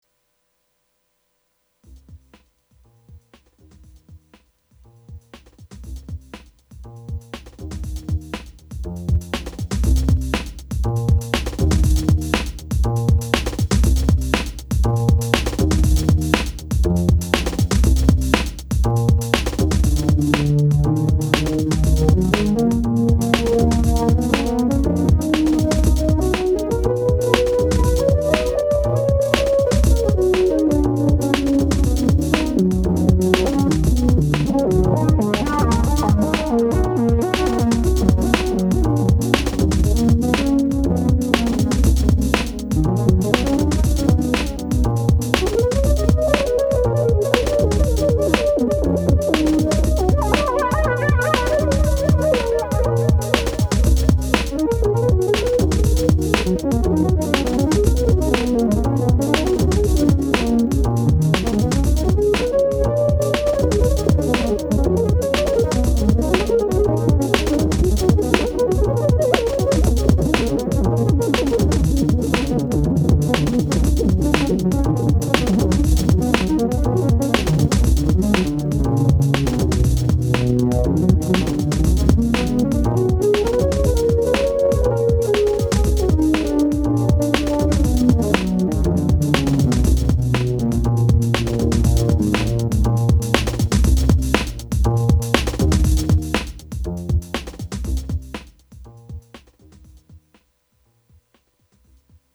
ドラム、ベース、フルート、パッドの４つの楽器がセットされたマルチ・パッチで、バッキングをサクッとやる時には重宝します。
以上のセッティングで、フルートの手弾きソロとドラムとベースのバッキング入りでアドリブ演奏した曲が以下です。
今回の録音は、簡単に済ませるためにステレオICレコーダーにヘッドフォン端子からオーディオ・ケーブルで繋いでダイレクトに録音しましたが、Reasonで手弾きのMIDI信号をトラックに録音してソングをWAVファイルに書き出したり、以下の記事で説明している「Voicemeeter」を使ったり、「Rewire」などでDAWソフトへのループバックの方法などいろいろあるので、都合の良い方法で録音してください。
これとReason Limitedのサウンドを比べても、決して引けを取らない出音ですし、雰囲気的にはアコースティックっぽいReason Limitedのサウンドの方が好みの人も居るかと思います。